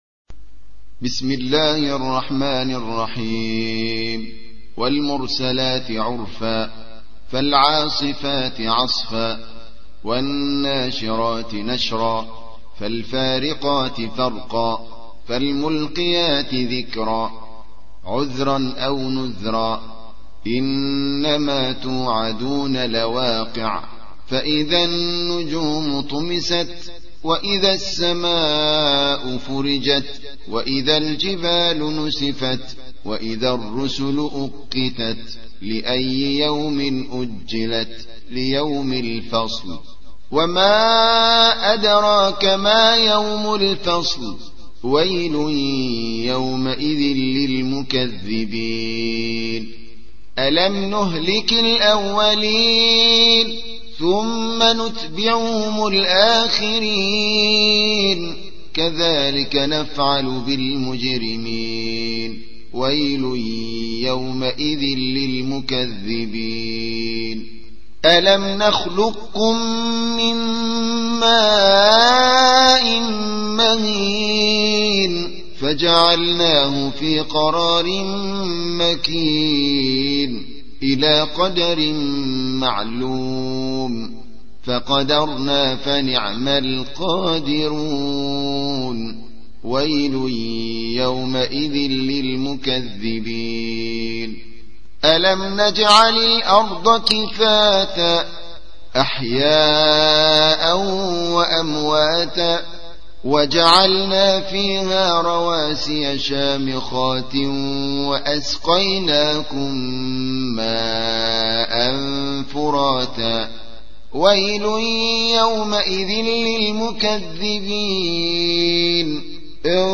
77. سورة المرسلات / القارئ